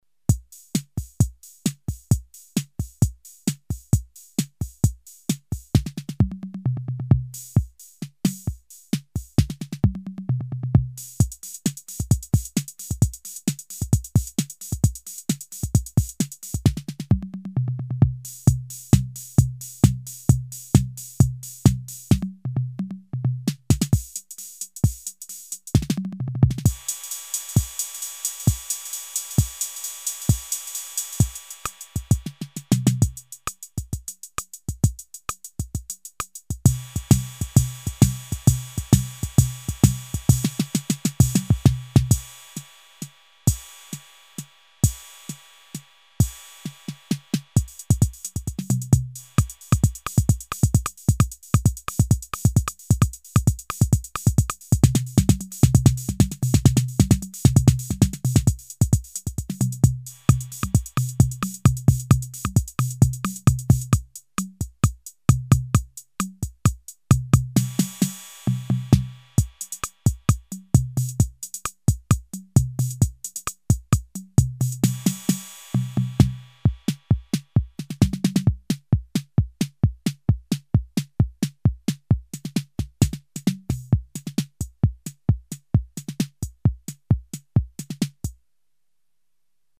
Preset analog drum machine originally releases to expand the ROLAND HP300-400 piano keyboards system.
Pattern rhytms are: rock (x3), waltzer, bossanova, samba, disco, rhumba, chacha, swing (x2), slow rock, shuffle, enka, march and beguine.
demo hear ACCENT